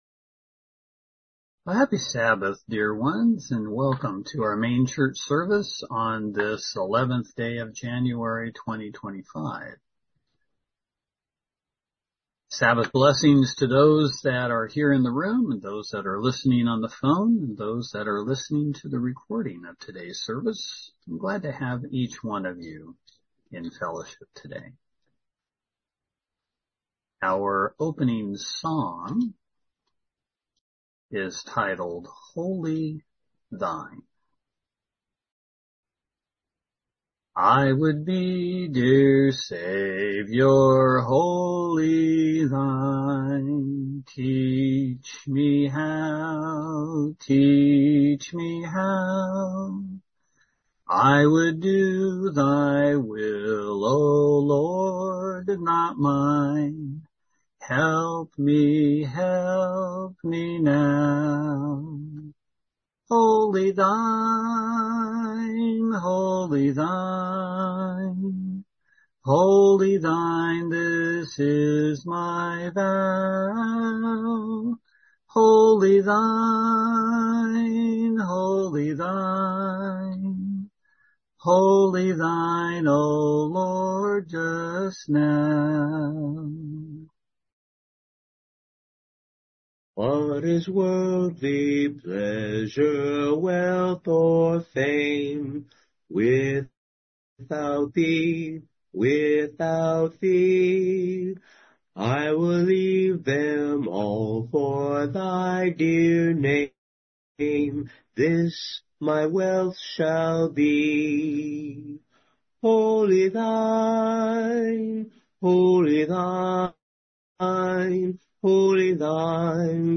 LTBL-sermon-(1-11-25).mp3